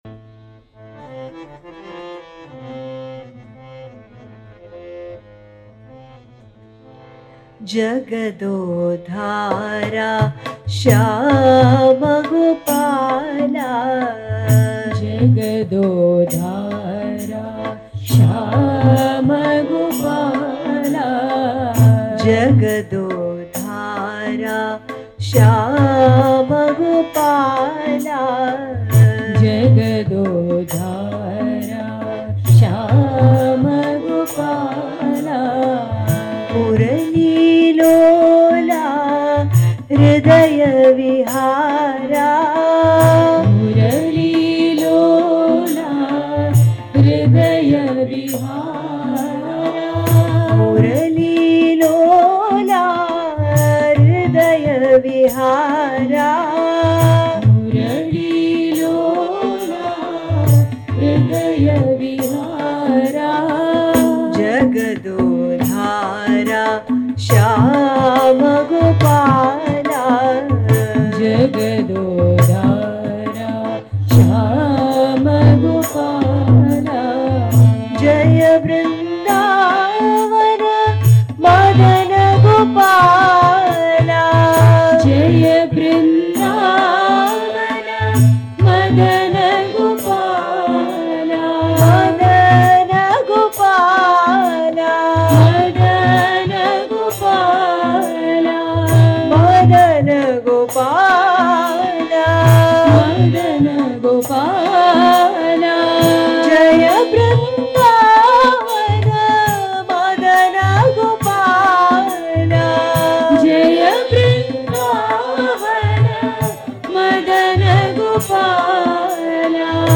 1. Devotional Songs
Sindhu Bhairavi / Bhairavi
8 Beat / Keherwa / Adi
Medium Slow
1.5 Pancham / C#
5.5 Pancham / G#